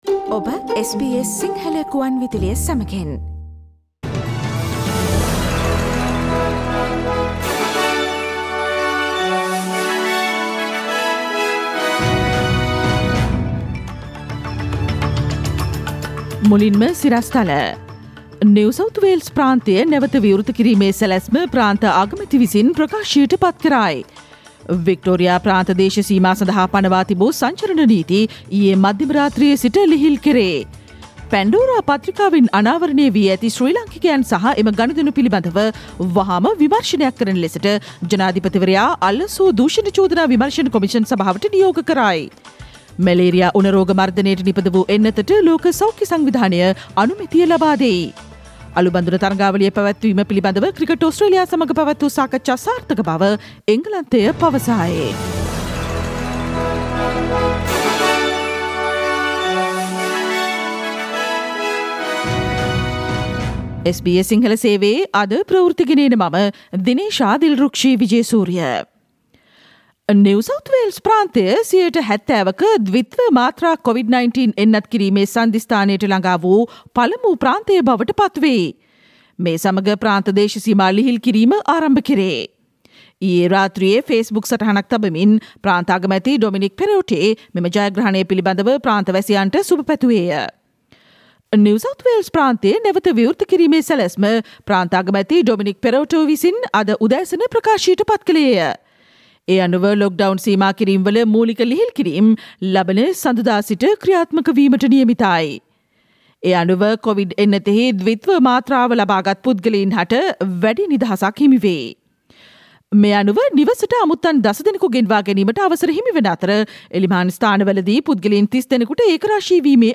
Listen to the latest news from Australia, Sri Lanka, across the globe, and the latest news from the sports world on SBS Sinhala radio news bulletin – Thursday 7 October 2021